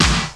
SD LIPPER.wav